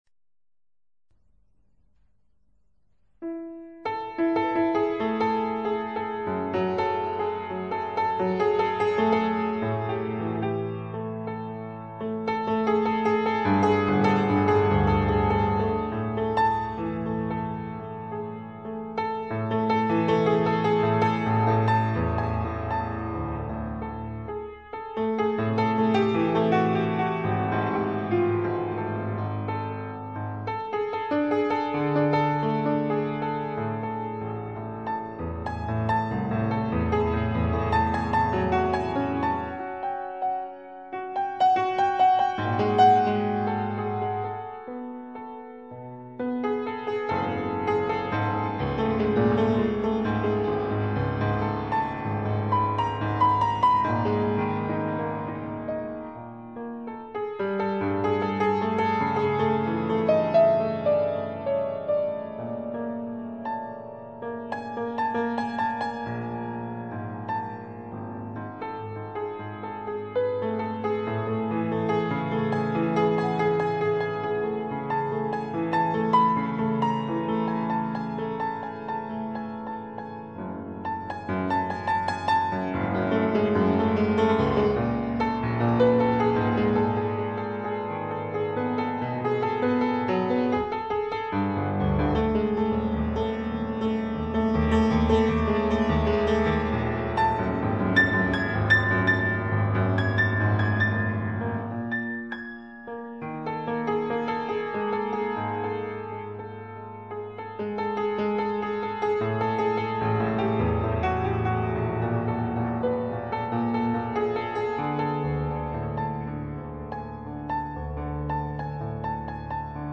FOR PIANO SOLO